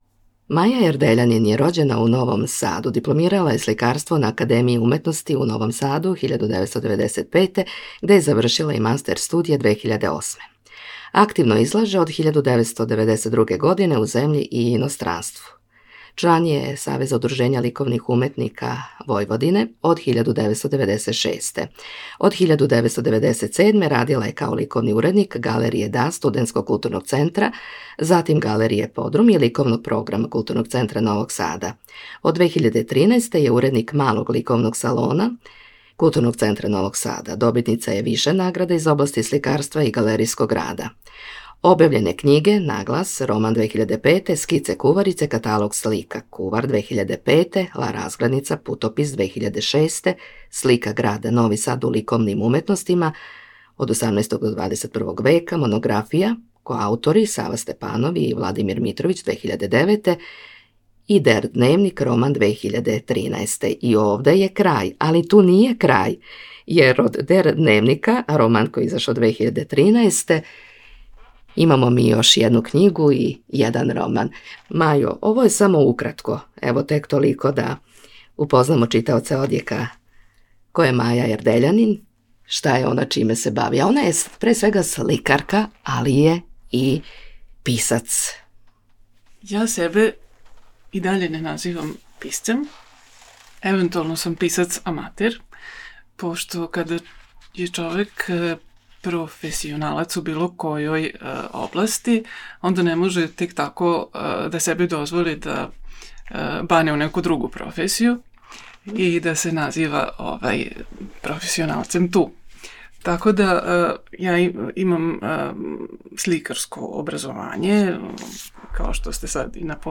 Razgovor
u studiju Saveza slepih Vojvodine